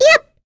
Rephy-Vox_Attack2.wav